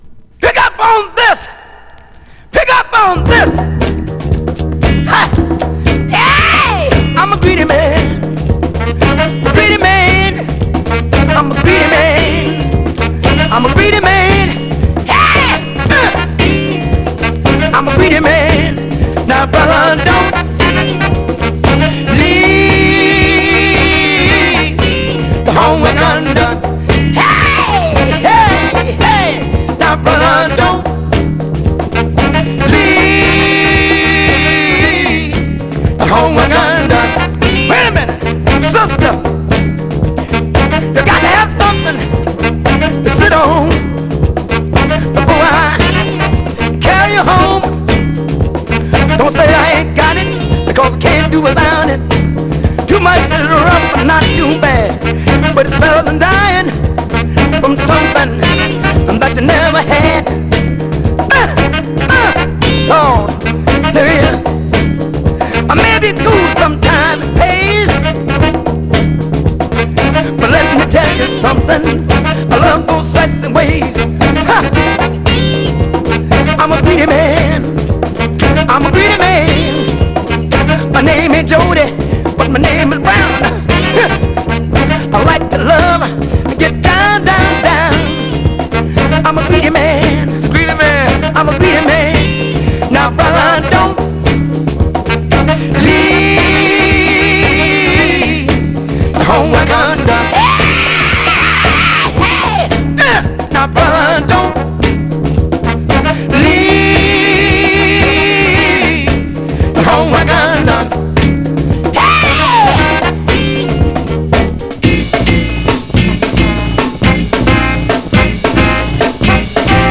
Funk　ファンク・クラシック
試聴曲＝Side A
※実物の試聴音源を再生状態の目安にお役立てください。